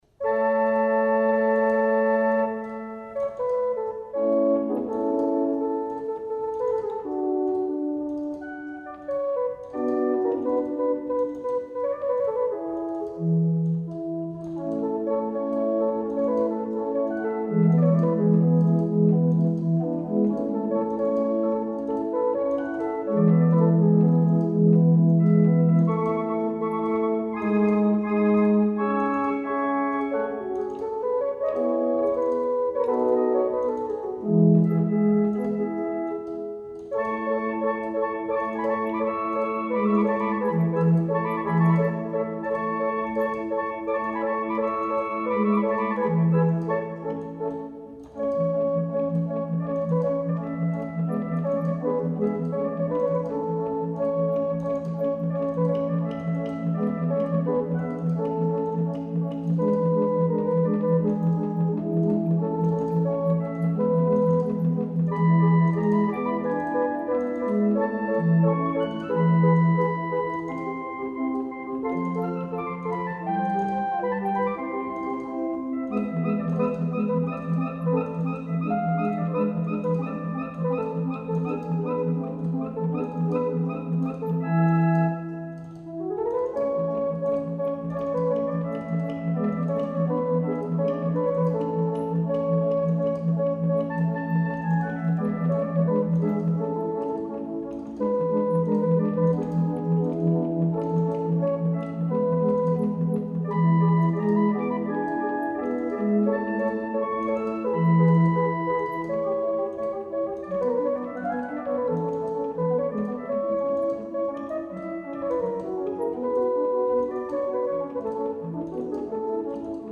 flauto 1818
Flauto a canne prob. opera di Anton Bayer (Vienna), 1818